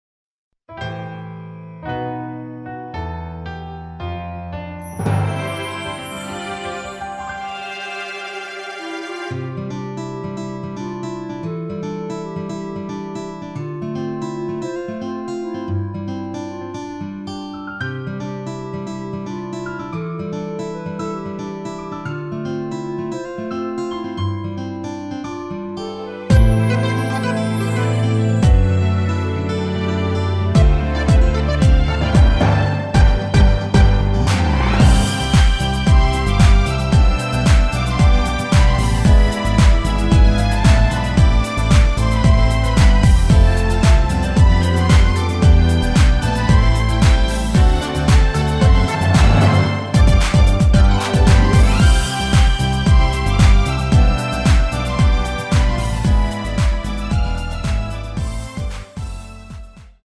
원키 멜로디포함된 MR 입니다.(미리듣기 참조)
앞부분30초, 뒷부분30초씩 편집해서 올려 드리고 있습니다.
중간에 음이 끈어지고 다시 나오는 이유는